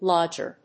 アクセント・音節lódg・er
音節lodg･er発音記号・読み方lɑ́ʤər|lɔ́-